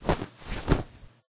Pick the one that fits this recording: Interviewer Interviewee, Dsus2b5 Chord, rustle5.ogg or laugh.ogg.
rustle5.ogg